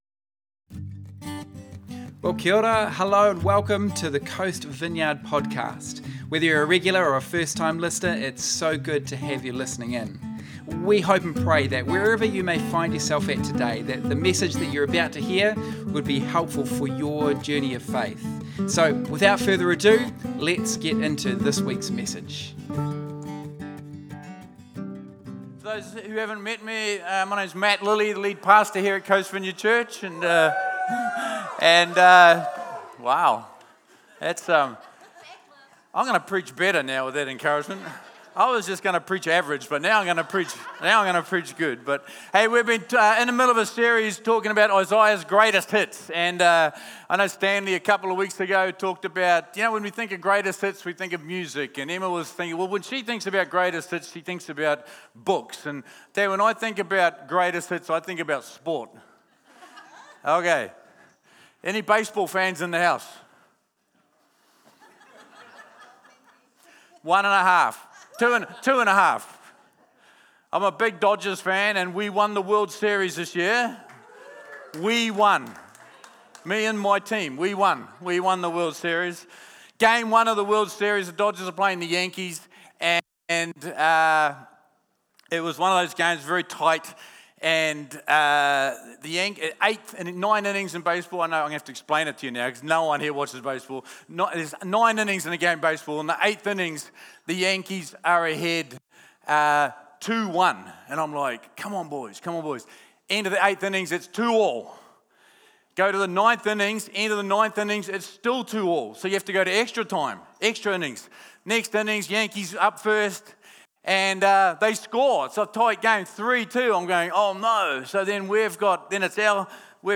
Sunday Message Audio